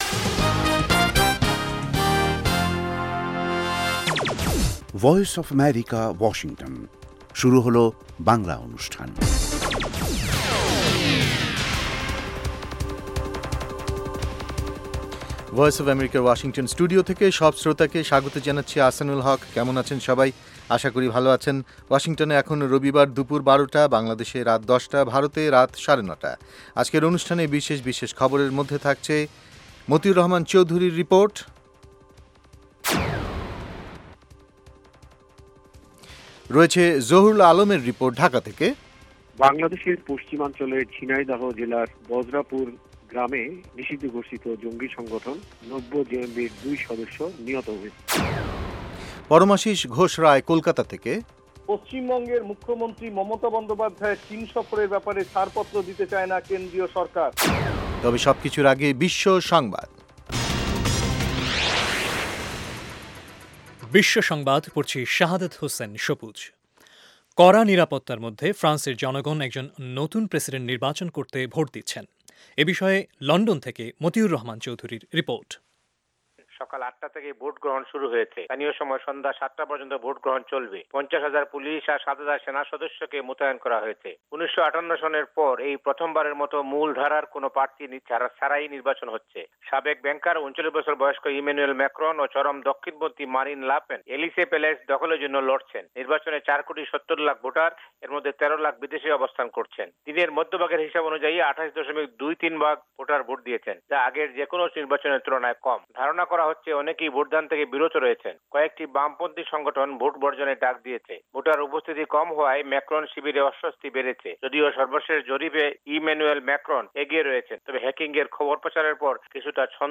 অনুষ্ঠানের শুরুতেই রয়েছে আন্তর্জাতিক খবরসহ আমাদের ঢাকা এবং কলকাতা সংবাদদাতাদের রিপোর্ট সম্বলিত বিশ্ব সংবাদ, এর পর রয়েছে ওয়ার্ল্ড উইন্ডোতে আন্তর্জাতিক প্রসংগ, বিজ্ঞান জগত, যুব সংবাদ, শ্রোতাদের চিঠি পত্রের জবাবের অনুষ্ঠান মিতালী এবং আমাদের অনুষ্ঠানের শেষ পর্বে রয়েছে যথারীতি সংক্ষিপ্ত সংস্করণে বিশ্ব সংবাদ।